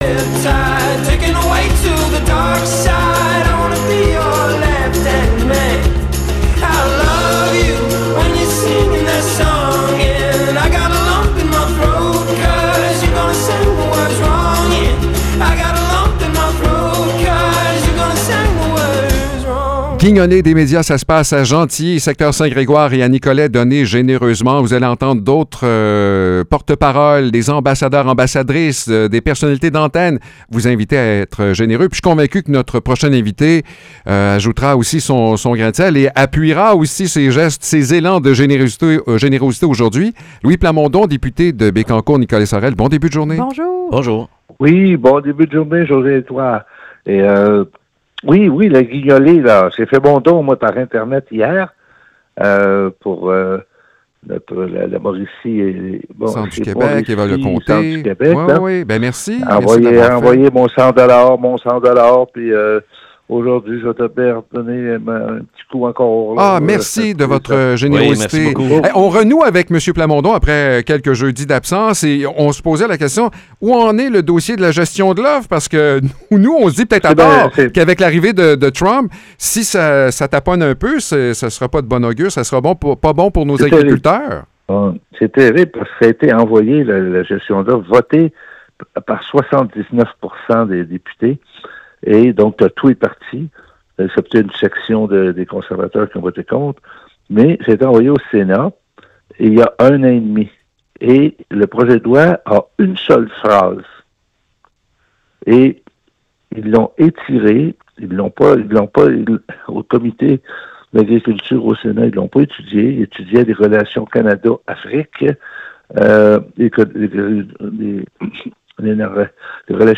Louis Plamondon, député de Bécancour Nicolet Saurel à la Chambre des communes, nous parle des dernières nouvelles du monde politique.